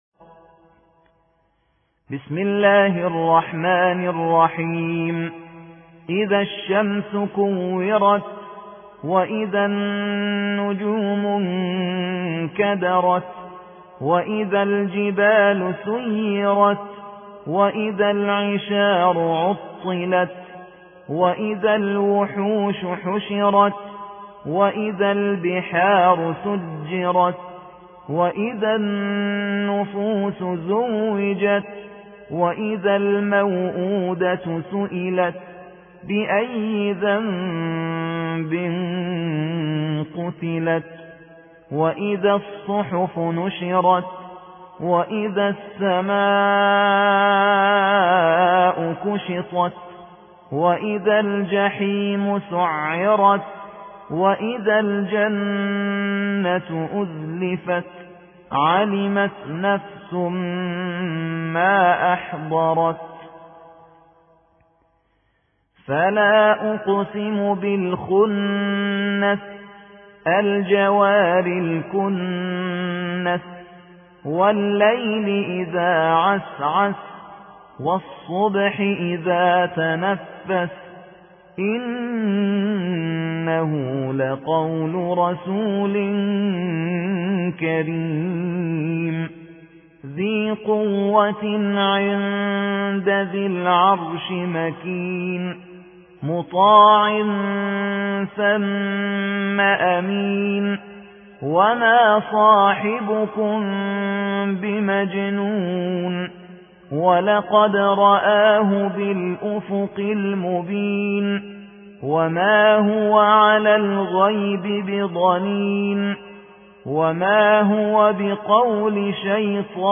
81. سورة التكوير / القارئ